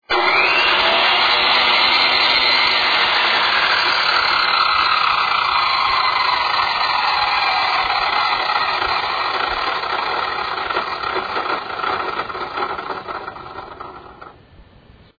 ベアリング交換後
交換後は、少しゴロゴロ音が大きくなったように感じるが、モーターピニオンとギヤとのバックラッシュか、減速時にスラスト方向に若干動くので、 歯あたりがずれて音がしているのではないかと思う。
通電OFF後、回転停止までの堕走時間は、交換前は約7秒、交換後は約12秒と長くなっている。
shindaiwa-l100p-after.mp3